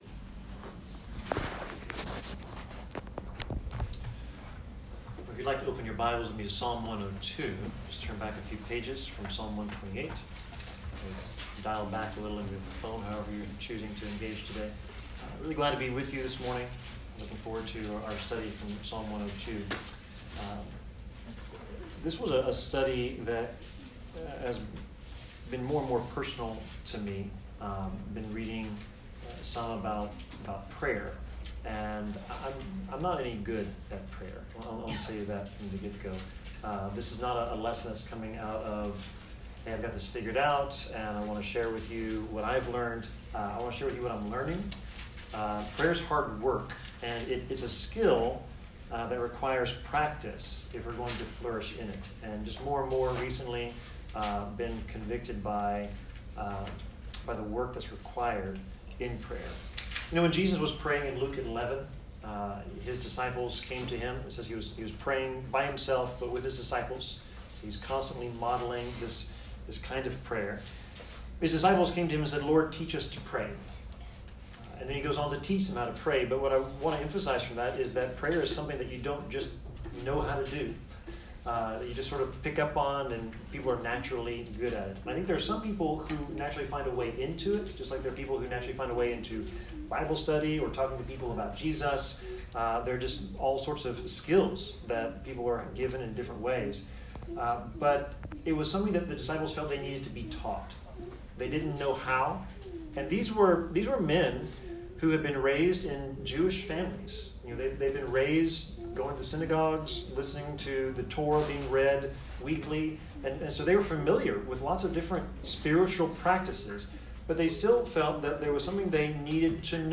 Service Type: Sermon